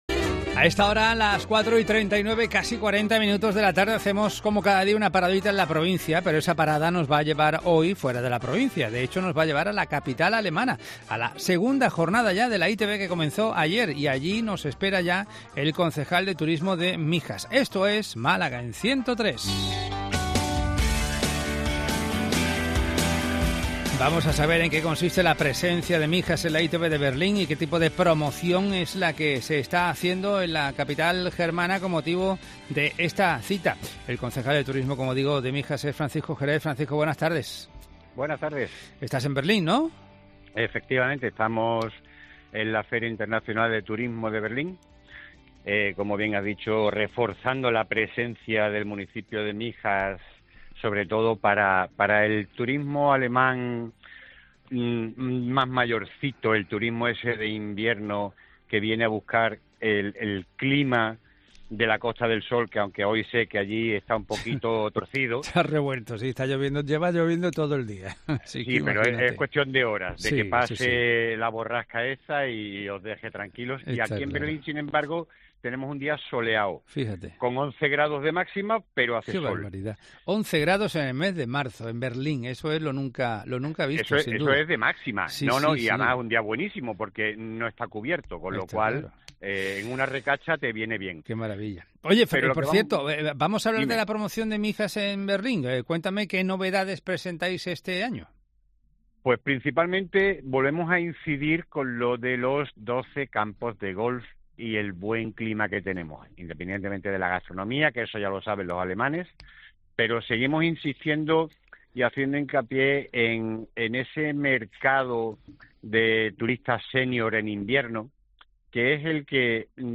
Entrevista con Francisco Jerez, concejal de turismo de Mijas